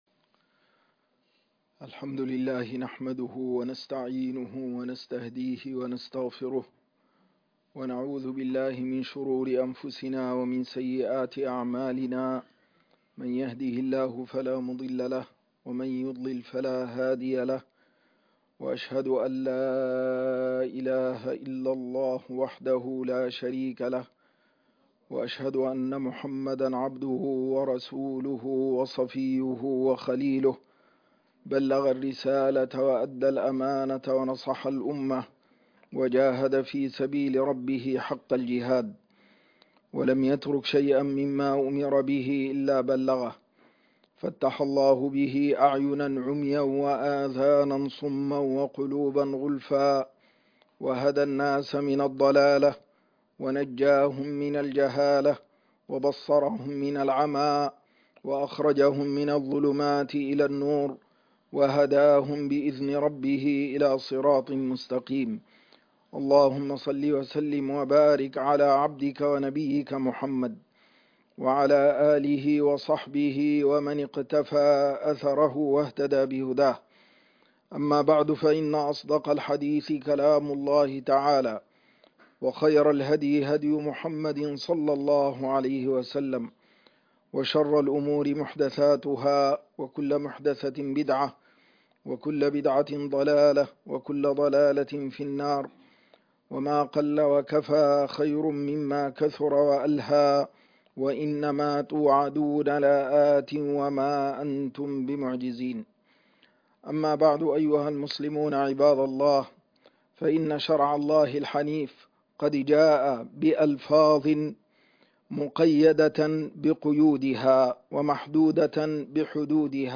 الشُــهداء خطبة الجمعة 31.12.2021 - الشيخ عبد الحي يوسف عبد الرحيم